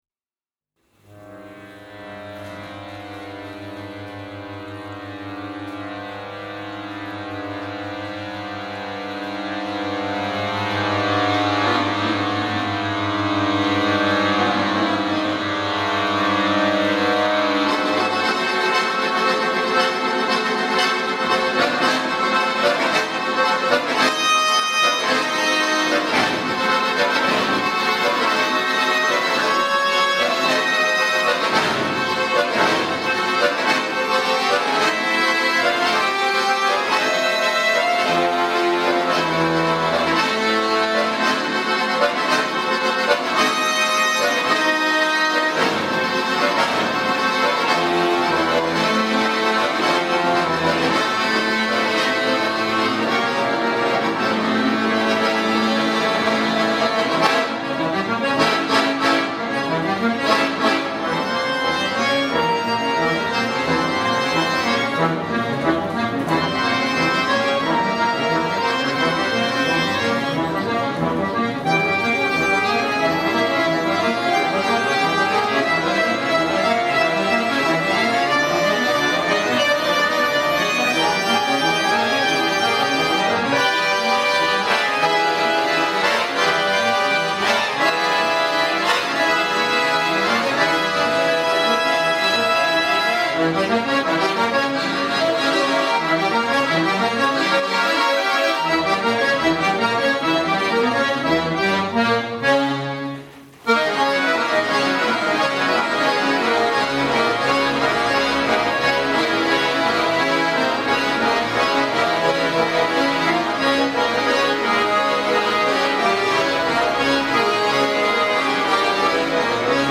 · ACCORDION ENSEMBLES
for accordion orchestra.